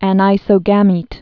(ăn-īsō-gămēt, ănĭ-)